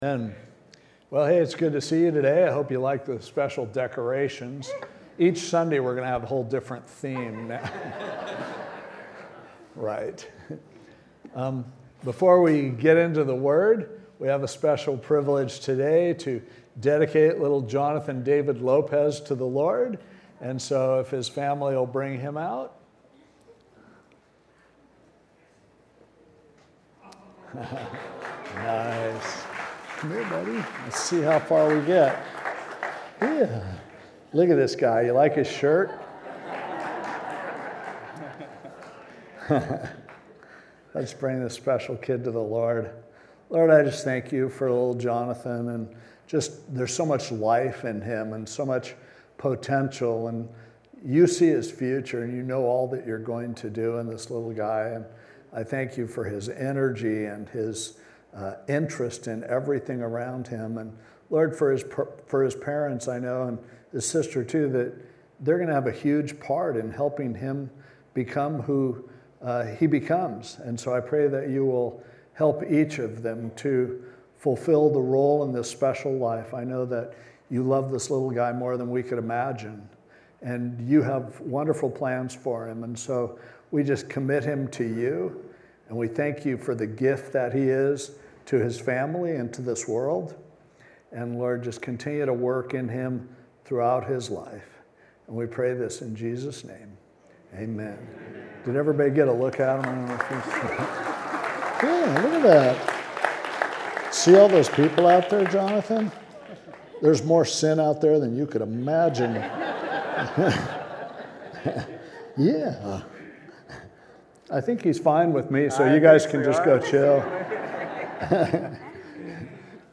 Messages | Pacific Hills Calvary Chapel | Orange County | Local Church